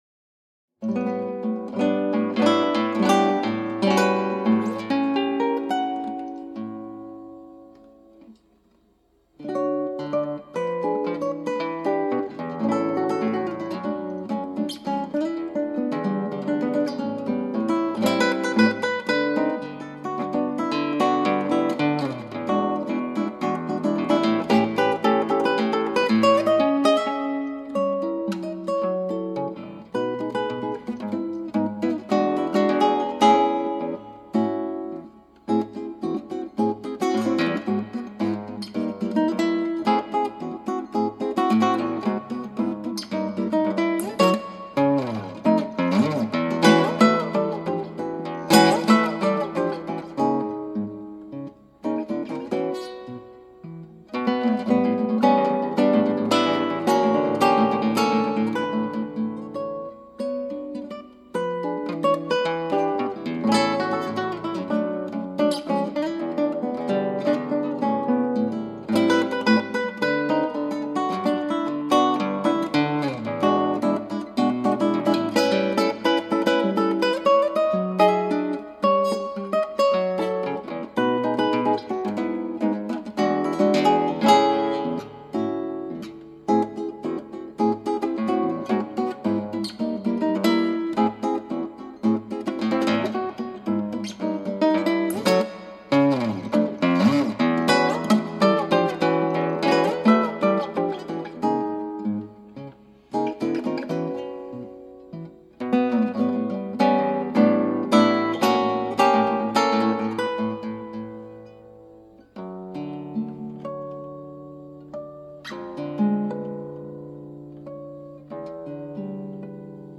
クラシックギター　ストリーミング　コンサート
一応全部弾いた・・・けど。指もつれてる・・・。
このバージョンはピチカートしてないです。